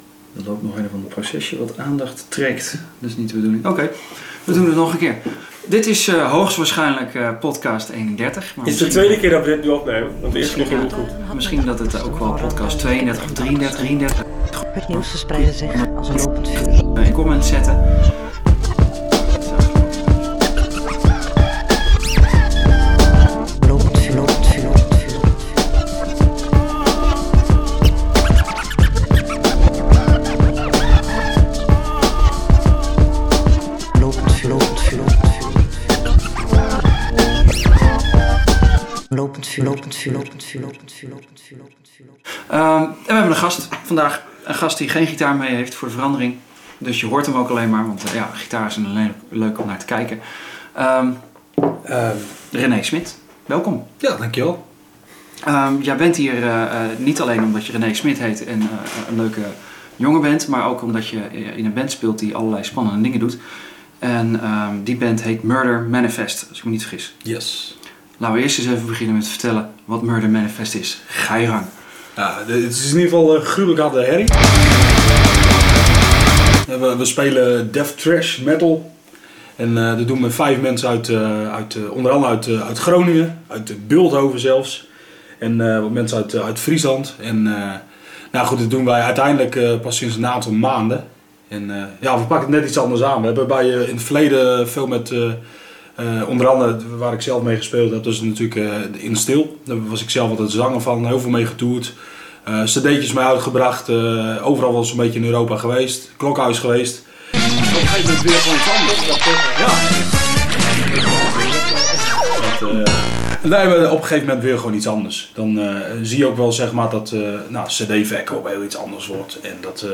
Luister naar zijn tips en schud je haren op hun muziek!.